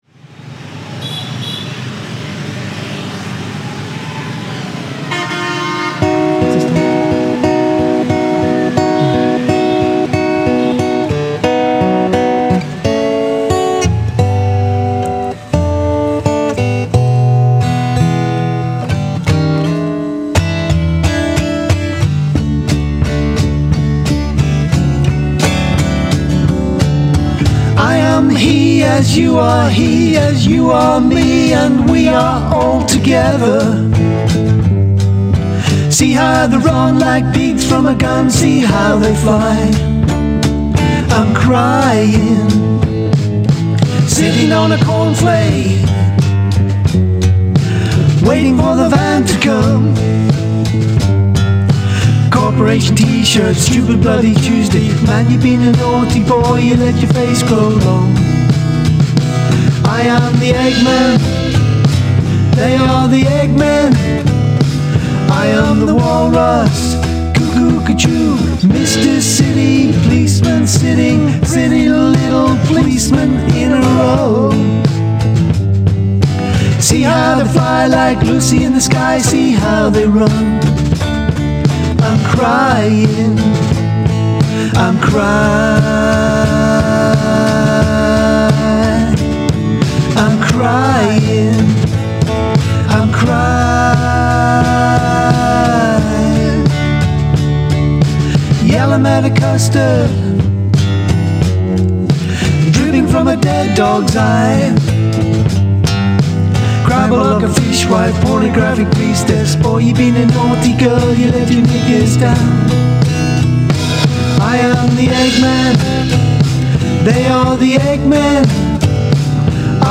folk and blues duo
Recorded at Hive Studios in London